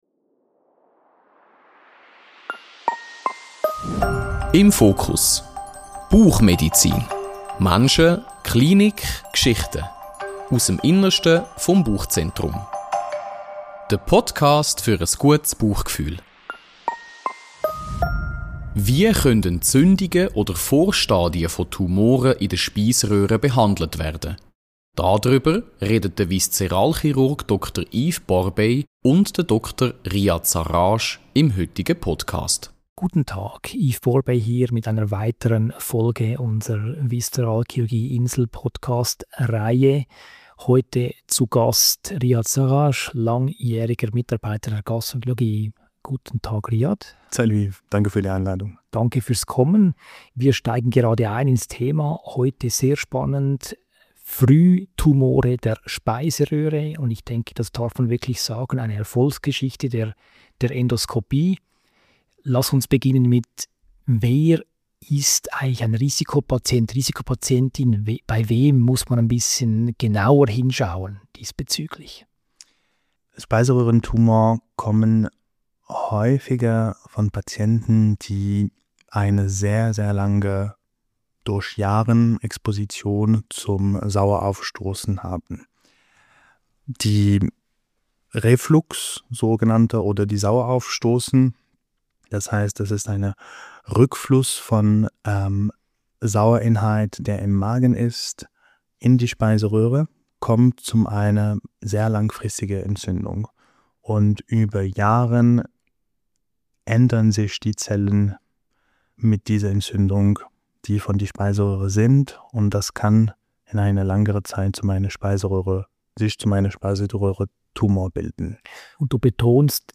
Ein sachliches und zugleich gut verständliches Gespräch über Prävention, Nachsorge und moderne Therapieansätze bei Erkrankungen der Speiseröhre.